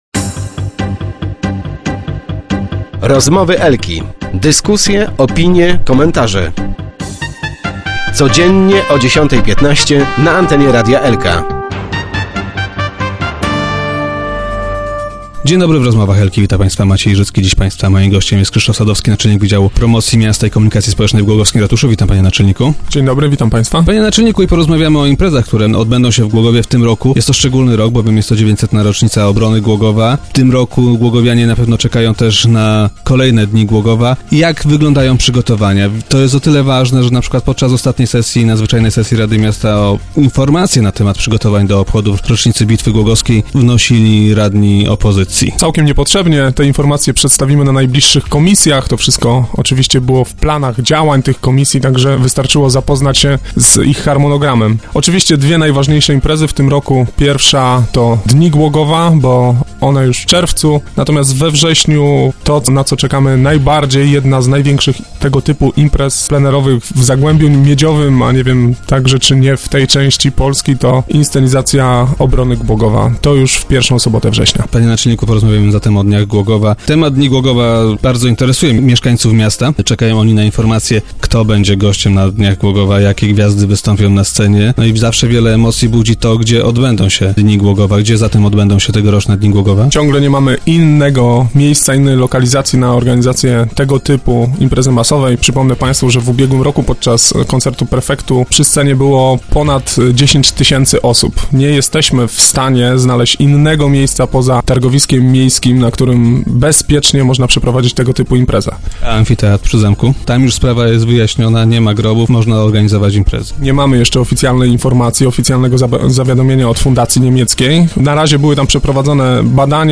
Start arrow Rozmowy Elki arrow Dni Głogowa na targowisku i starówce